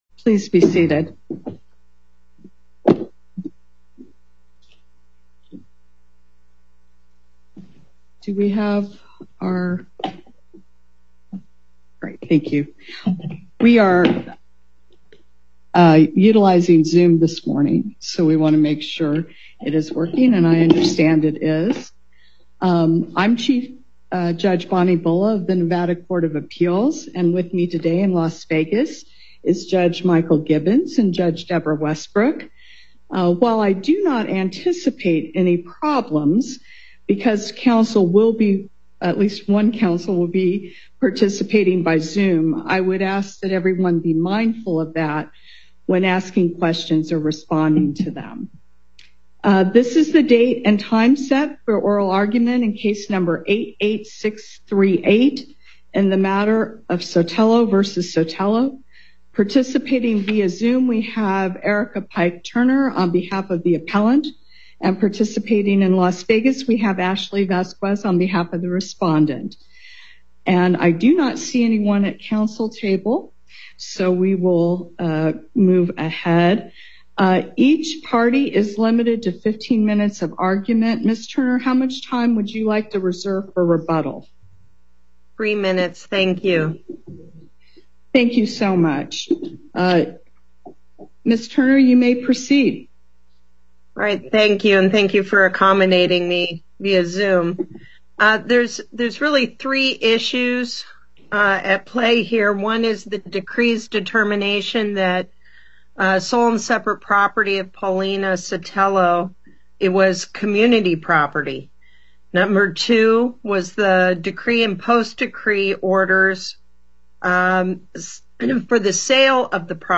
Before the Court of Appeals, Chief Judge Bulla presiding Appearances